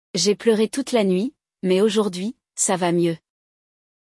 No episódio de hoje, vamos mergulhar em um diálogo emocionante entre dois amigos. Um deles passou a noite toda chorando e compartilha seu sentimento com o outro.